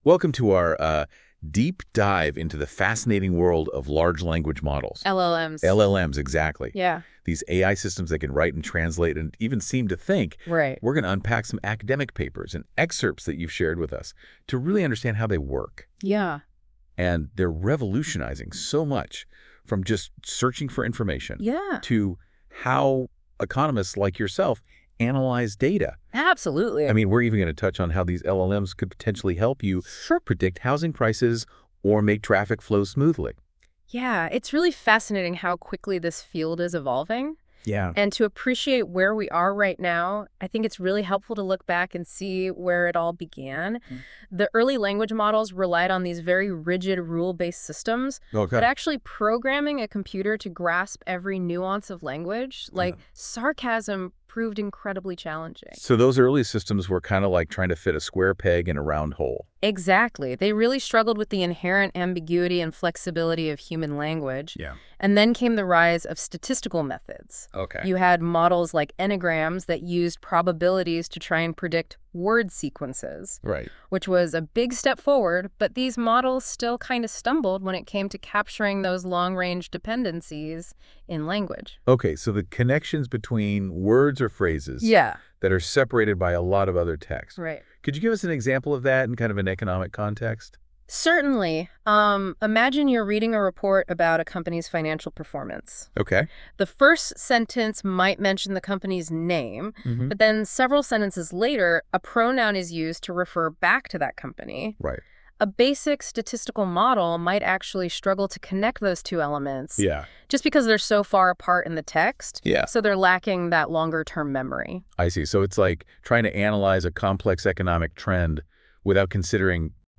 Chapter 1: An introduction to the principles of LLMs - Slides - Audio presentation by NotebookLM - Appendix on Self-attention in Transformers .